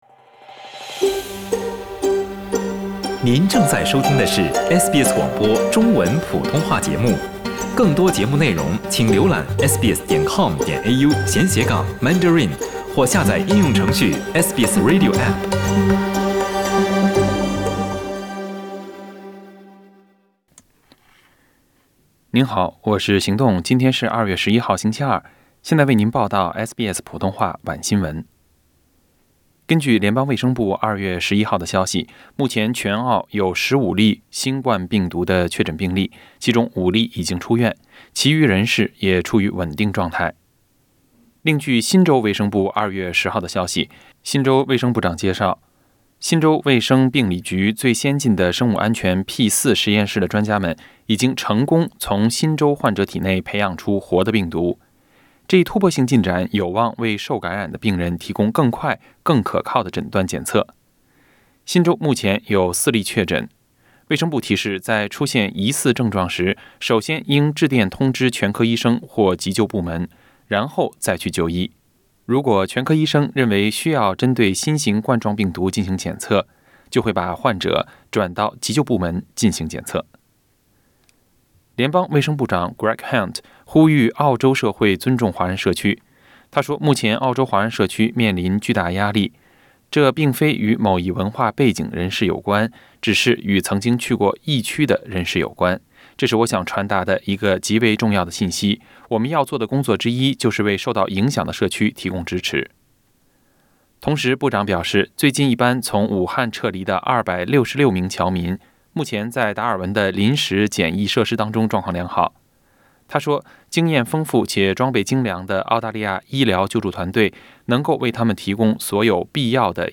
SBS晚新闻（2月11日）